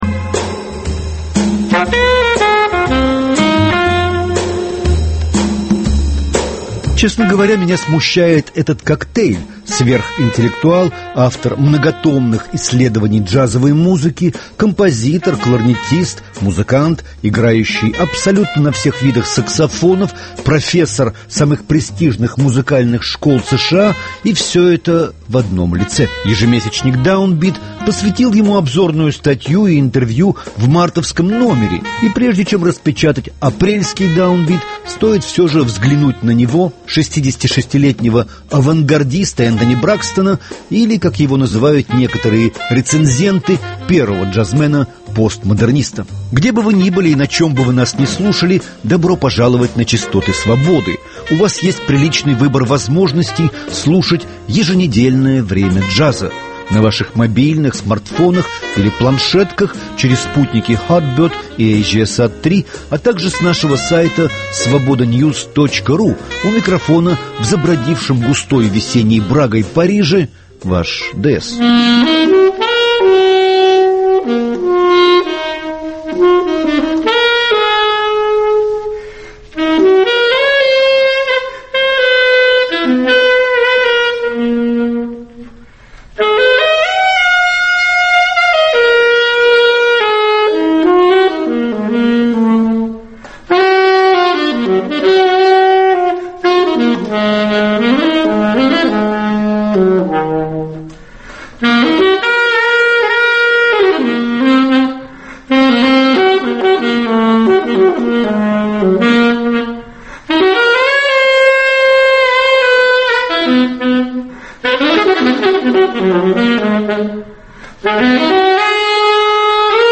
Кроме Бракстона во «Времени Джаза» на этой неделе: пианистка Мэриэн МакПартлэнд, саксофонист и флейтист Джеймс Муди, трубач Тад Джонс и кларнетист Пи Уи Рассел.